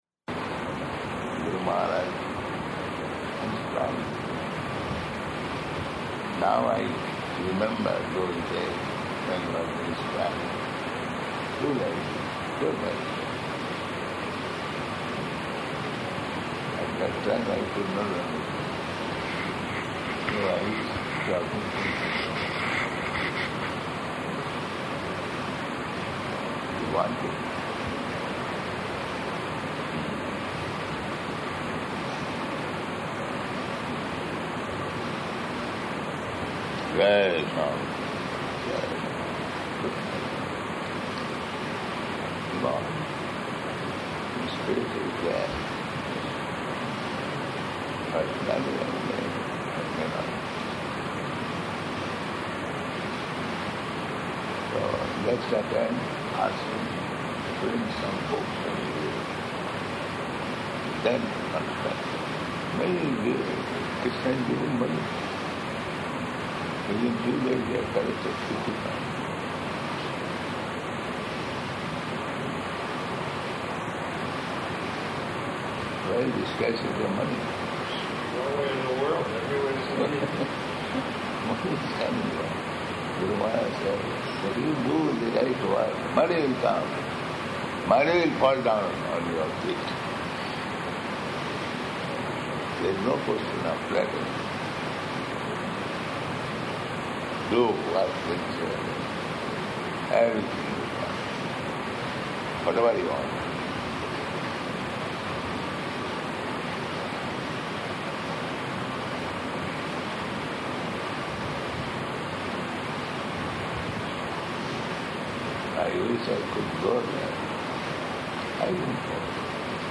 Conversation
Location: Vṛndāvana